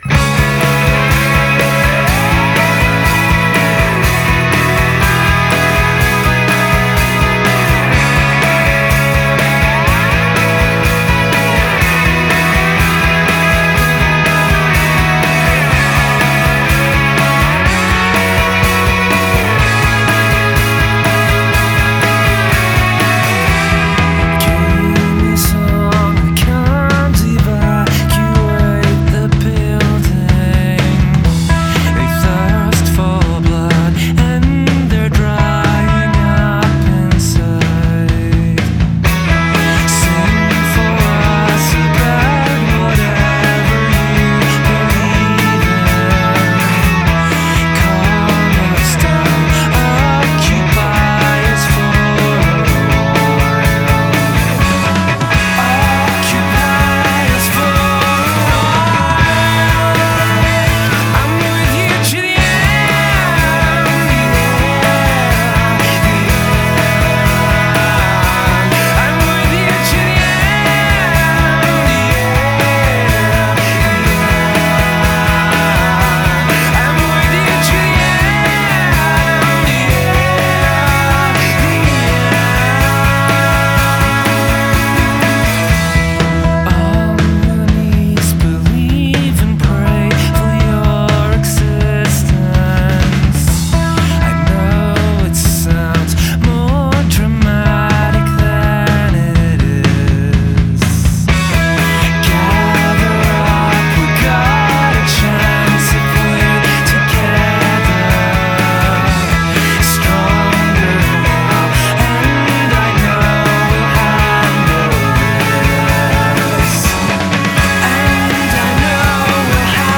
• Genre: Alternative Rock
schwedischen Alternative-Rockgruppe